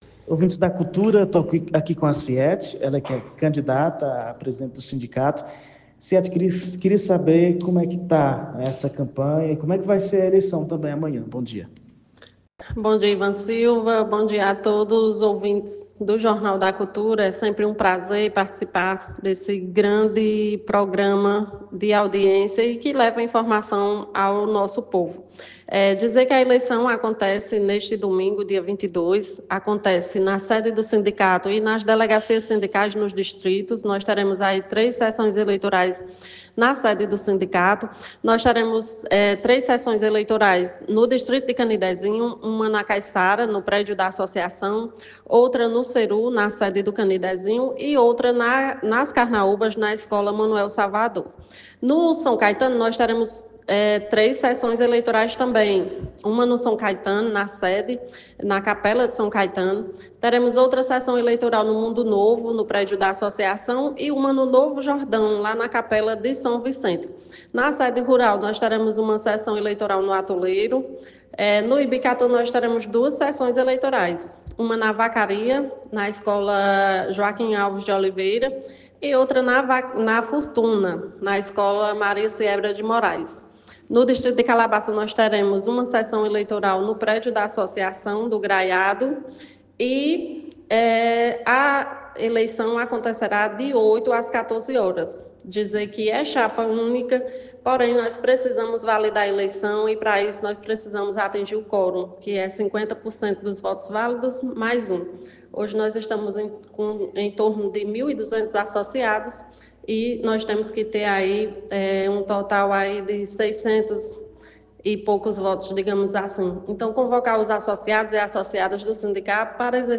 Ela gravou entrevista